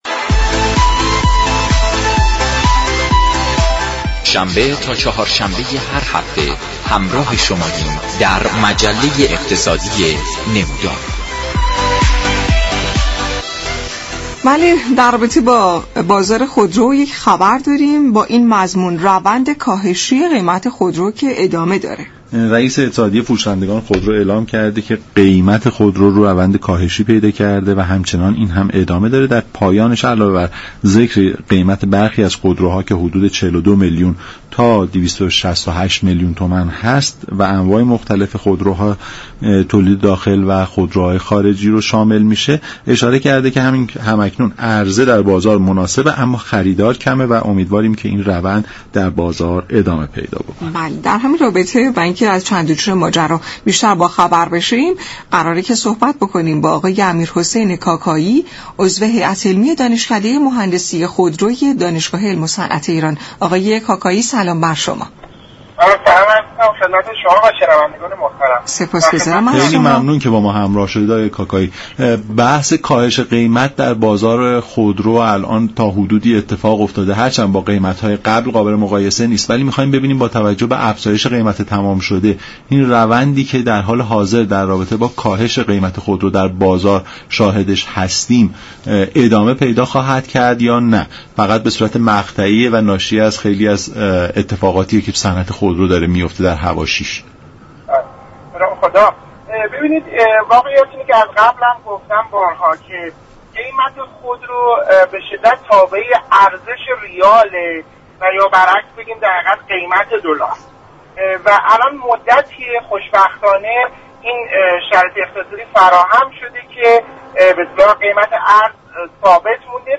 در گفت و گو با برنامه «نمودار»